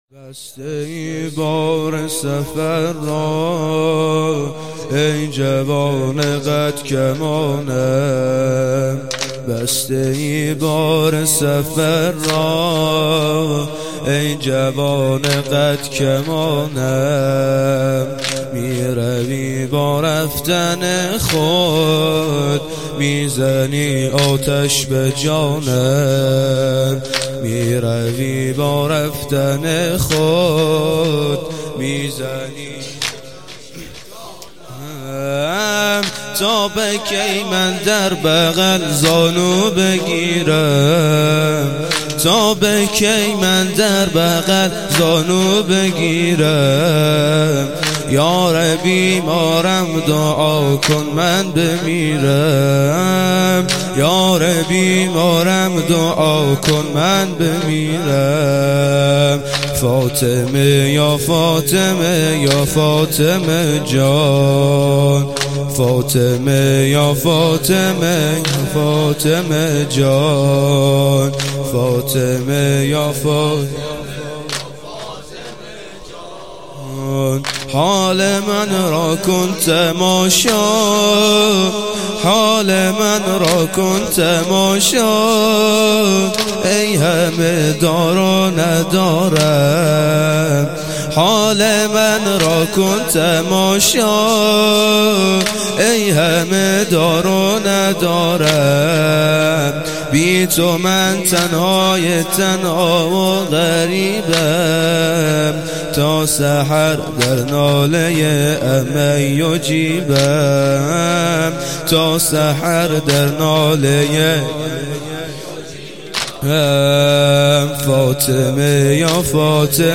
هیئت فرهنگی مذهبی فاطمیون درق